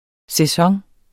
Udtale [ sεˈsʌŋ ]